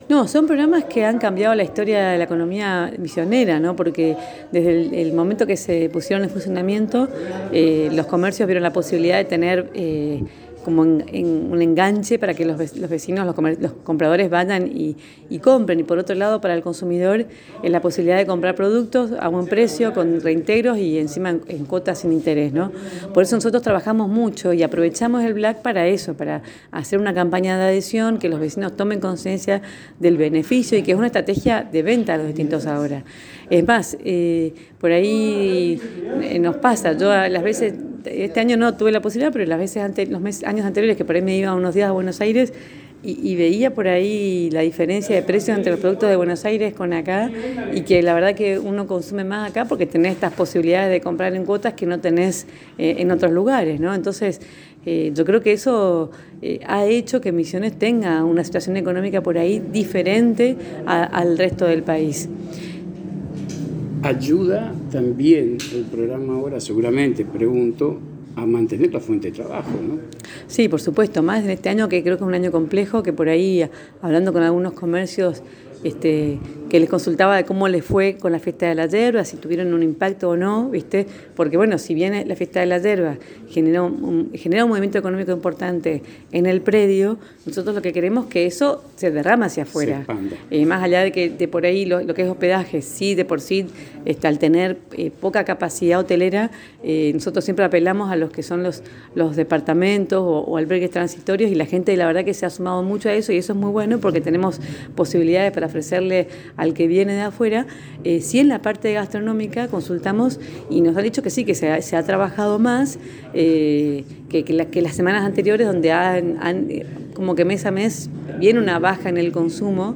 En dialogo exclusivo con la ANG a través de Éxito Fm, la Intendente de Apóstoles, María Eugenia, resaltó la importancia de los Programas Ahora.